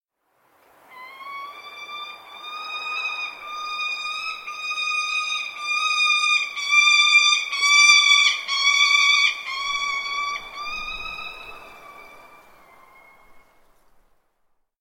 دانلود آهنگ شاهین از افکت صوتی انسان و موجودات زنده
دانلود صدای شاهین از ساعد نیوز با لینک مستقیم و کیفیت بالا
جلوه های صوتی